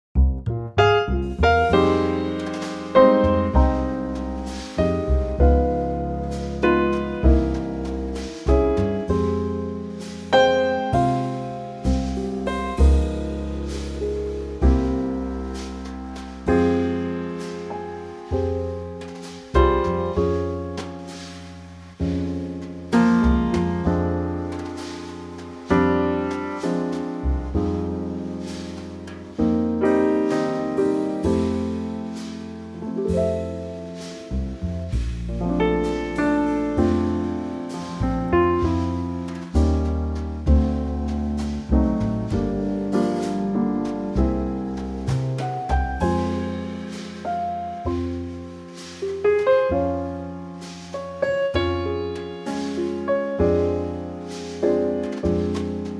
Unique Backing Tracks
key - Fm - vocal range - Ab to Ab
Trio arrangement